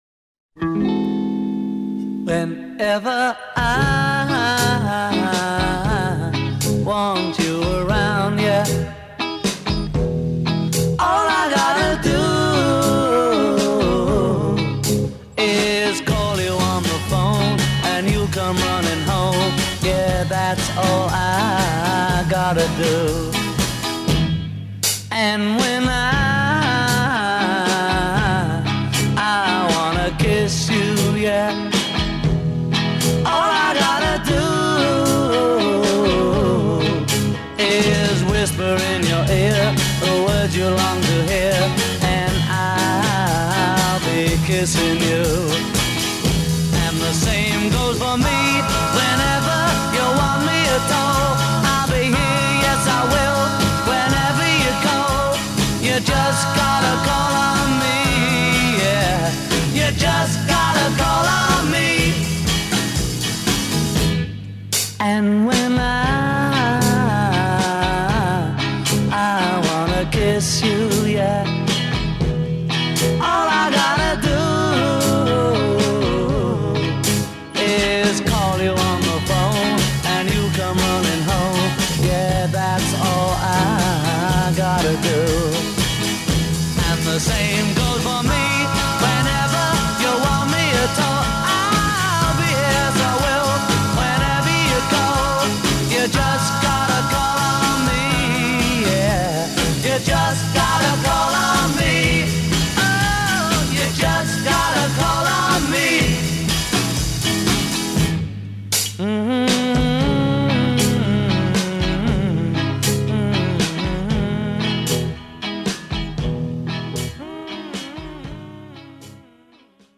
Караоке